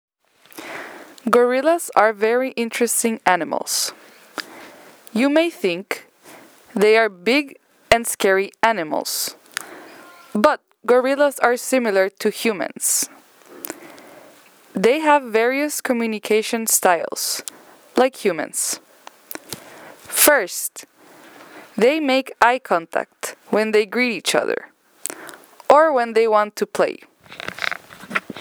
英語レシテーションの音声です！ こちらの音声を聞いて、自宅でも練習してください😊 …